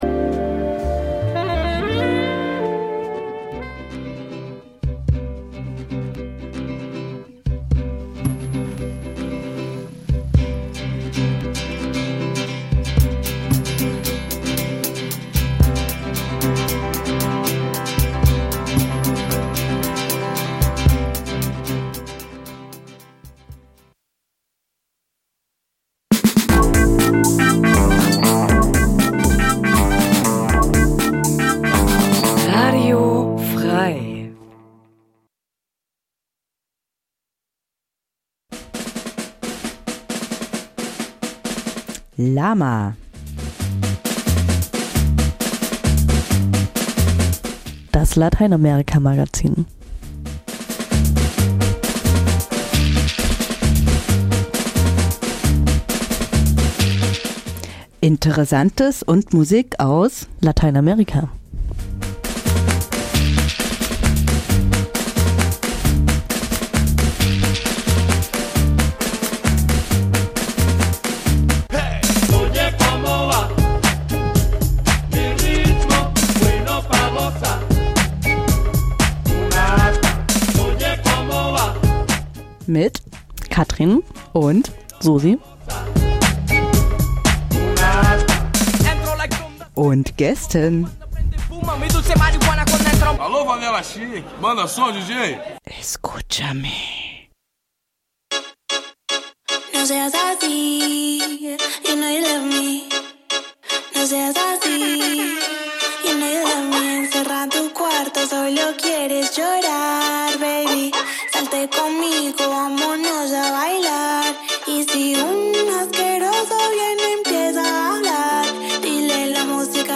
Bei den verschiedenen Veranstaltungen im Rahmen der Kraftwerkewoche habe ich Stimmen zum Thema Feminismus eingefangen.
Die Sendung ist wie immer voller Musik aus Lateinamerika, diesmal ausschlie�lich feministische K�nstlerinnen, die in ihren Liedern viele Themen der Sendung beschreiben.